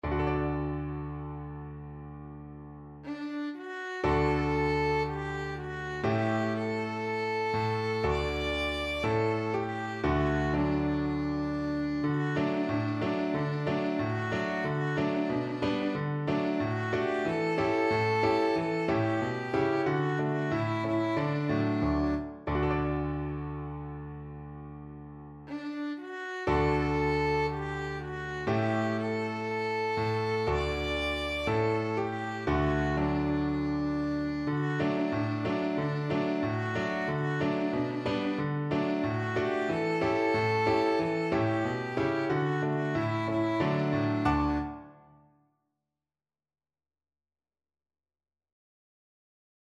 Christmas
Slow =c.60
2/2 (View more 2/2 Music)
D5-D6